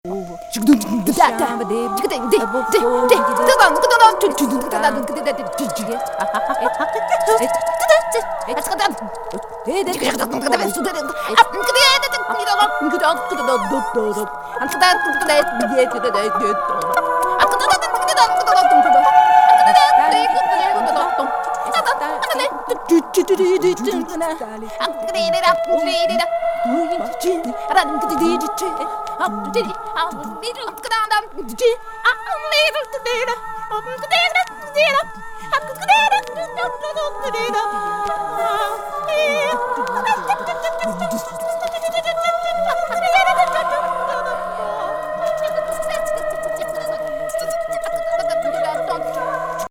最極北妖気漂うアヴァン・オペラ!